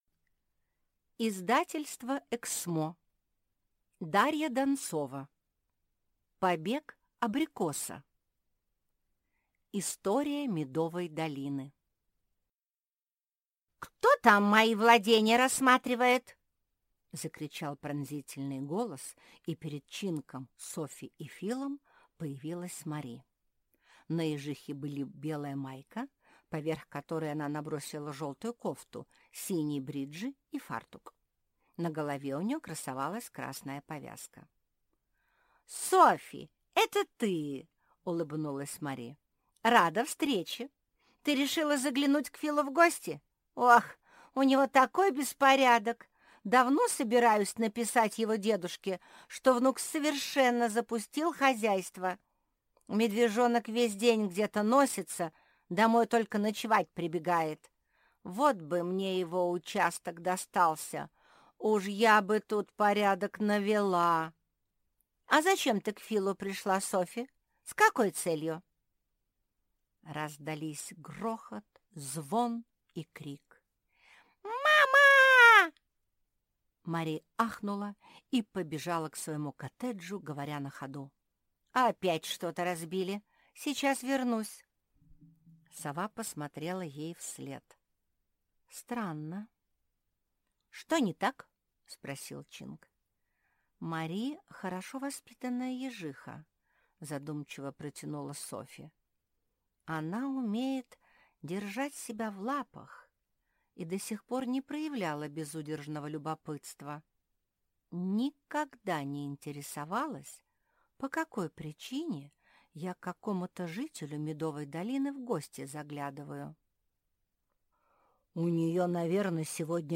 Аудиокнига Побег абрикоса | Библиотека аудиокниг
Прослушать и бесплатно скачать фрагмент аудиокниги